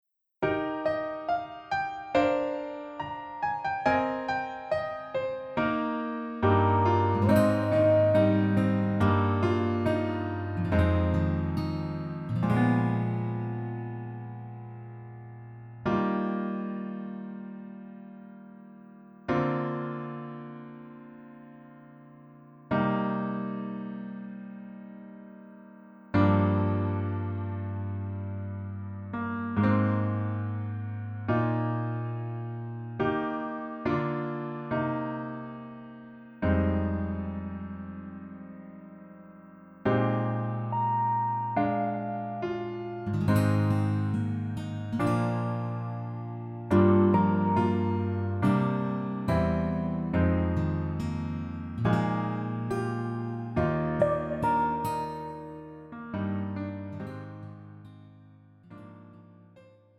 음정 -1키 4:13
장르 가요 구분 Pro MR